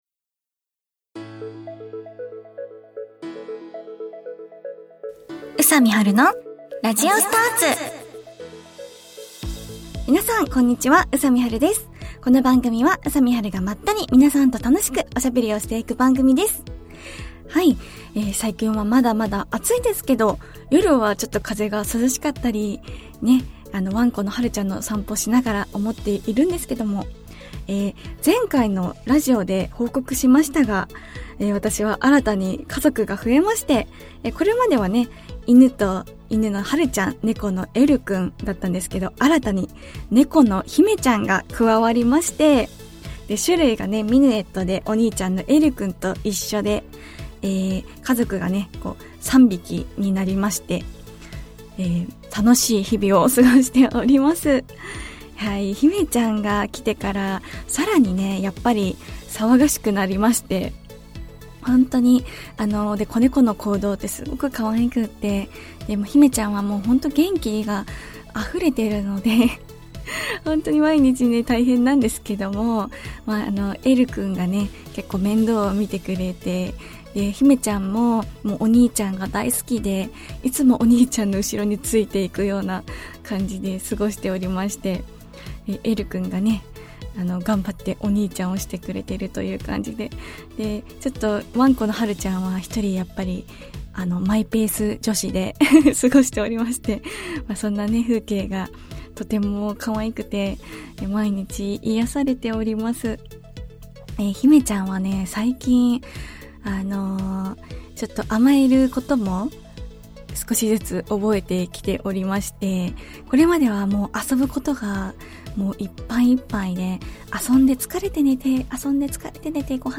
久しぶりの宅録配信です♪